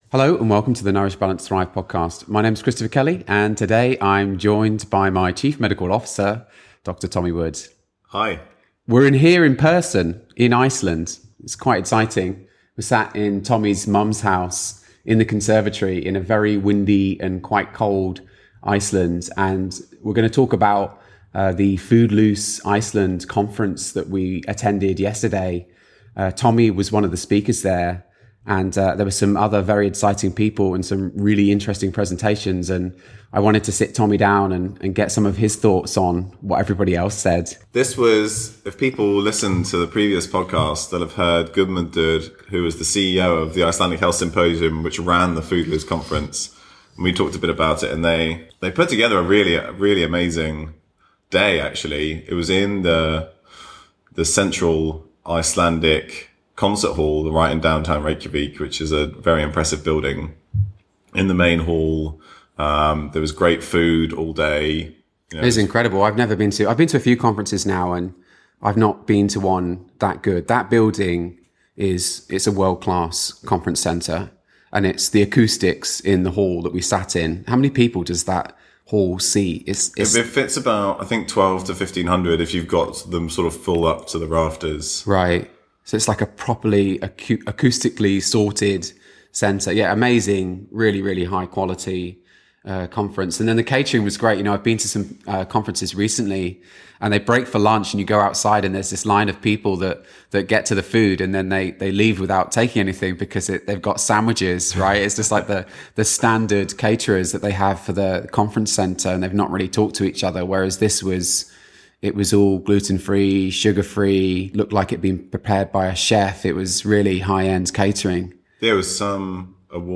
Here’s the outline of this interview